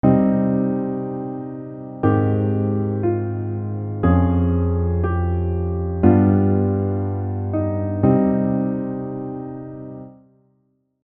Cm - Abmaj7 - Db/F - G7 - Cm
A minor tonic followed by a minor 6th interval often evokes a sense of yearning.
The two major chords in a row create a hopeful atmosphere in the music.